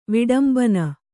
♪ viḍambana